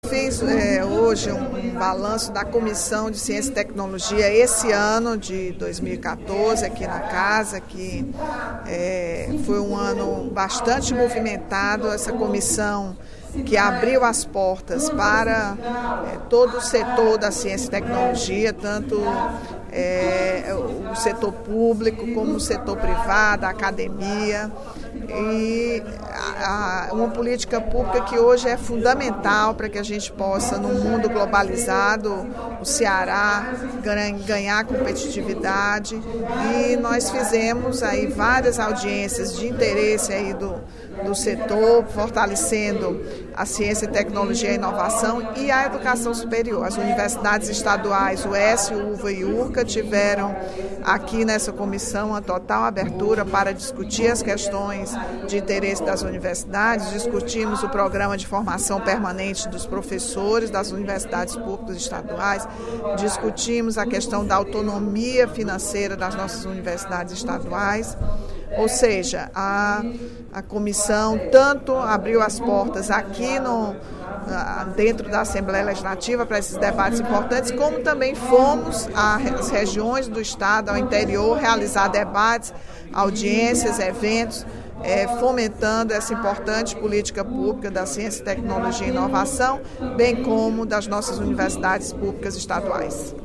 Durante o primeiro expediente da sessão plenária desta terça-feira (16/12), a deputada Rachel Marques (PT) fez um balanço das ações em 2014 da Comissão de Ciência e Tecnologia e Educação Superior da Assembleia Legislativa do Ceará, da qual é presidente.